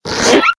toon_decompress.ogg